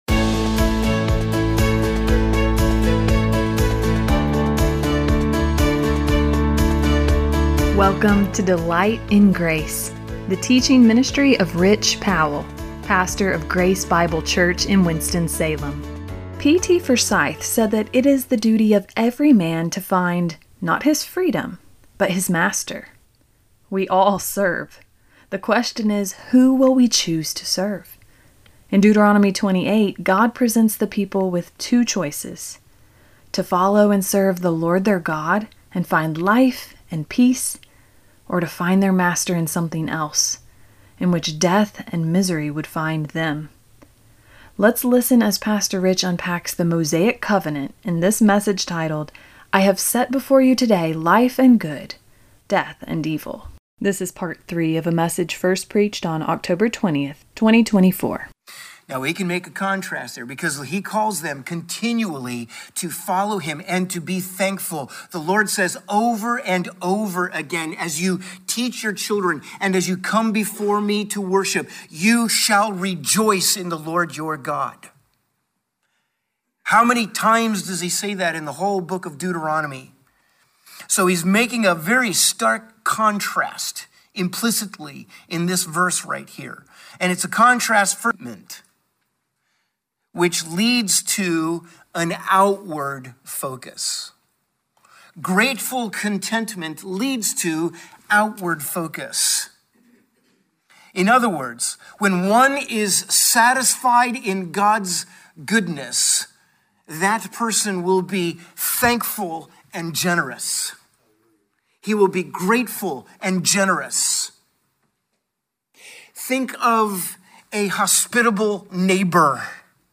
Podcast with Grace Bible Church